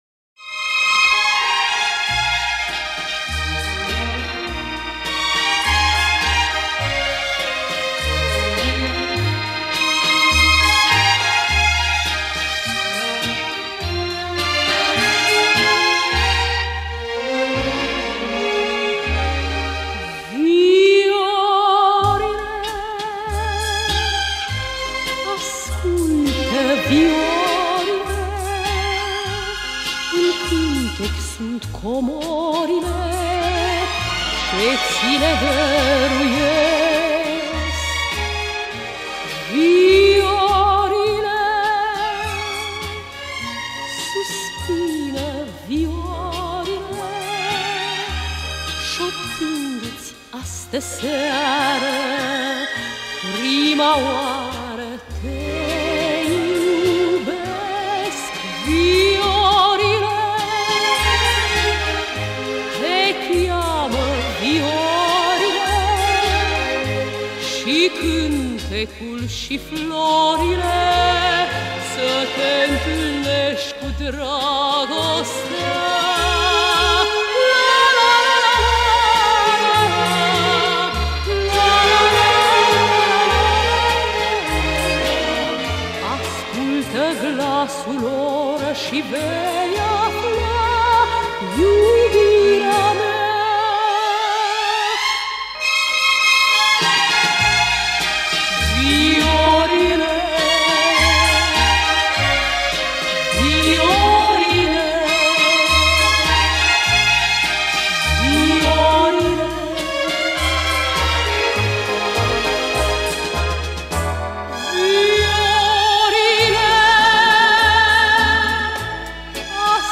Style:Easy Listening, Chanson, Schlager, Vocal
У нее редкий по силе и драматизму голос.